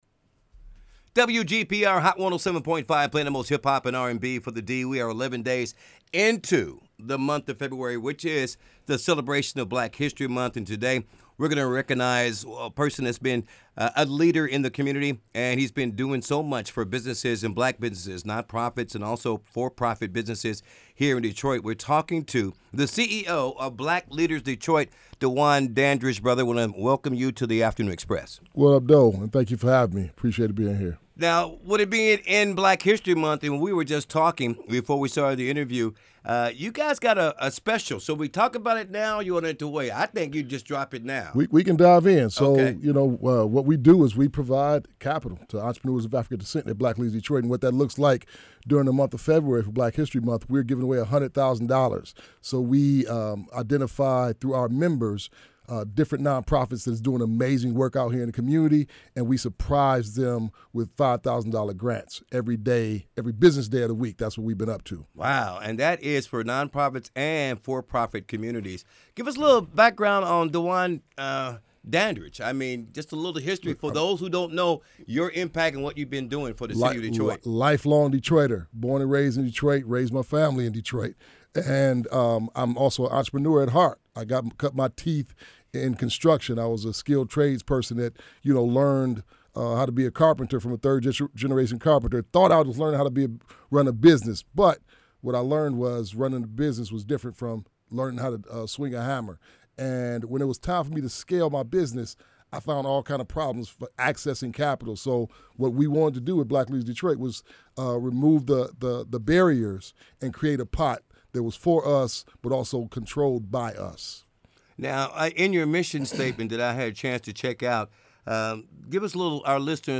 Hot 107.5 Interview